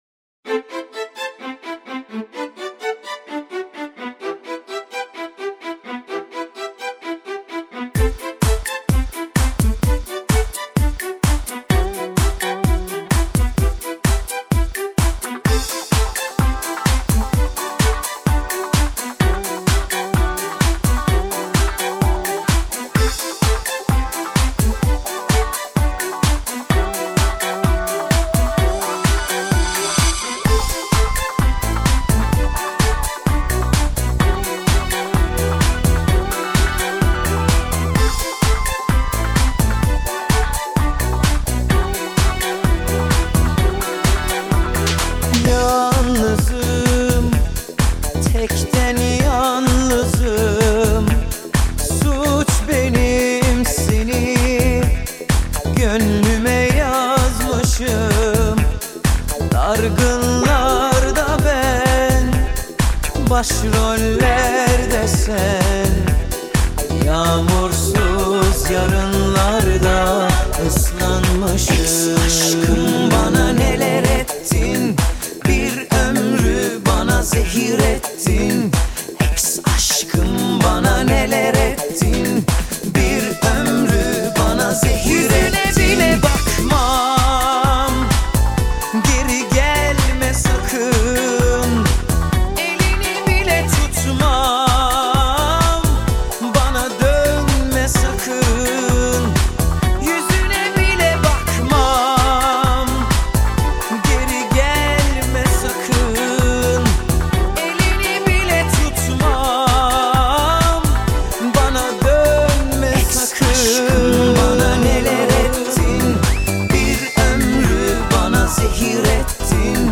Funk Mix